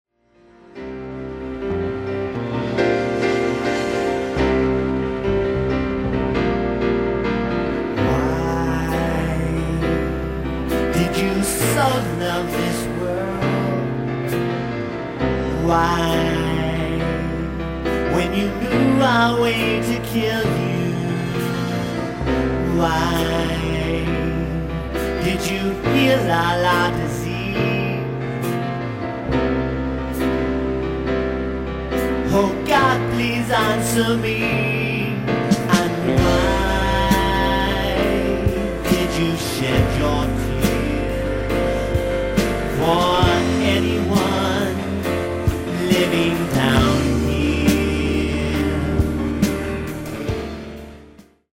Piano/Keyboards & Lead Vocals